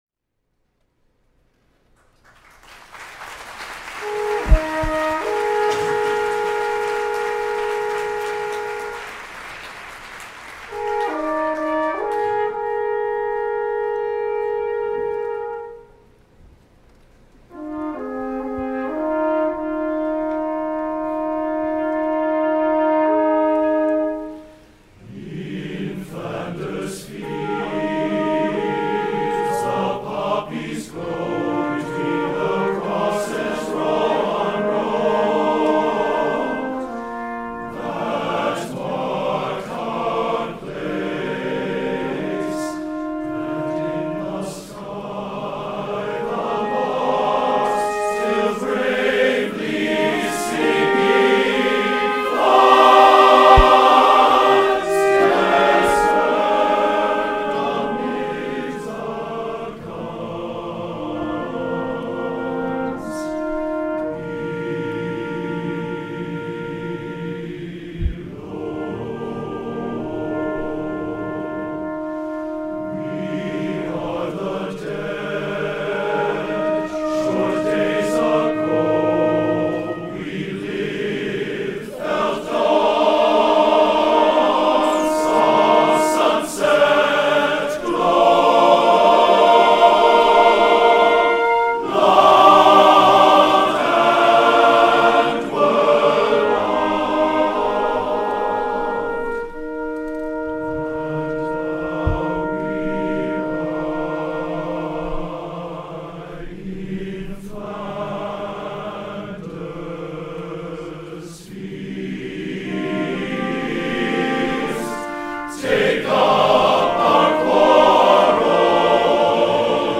Careful text setting, elegant writing, beautifully paced.
TB, piano (opt. trumpet and horn)